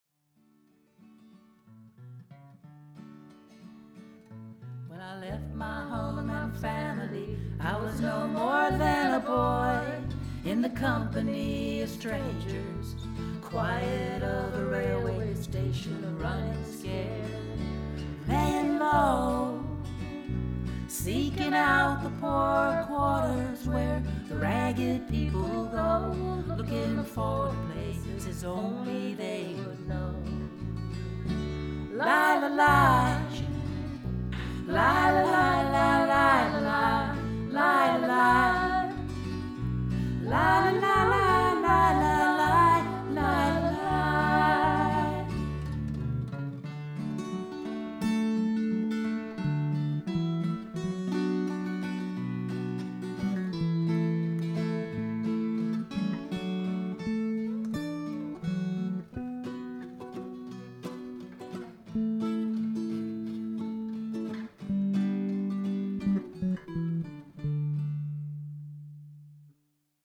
folk rock, smooth rock and alternative
Individual song clips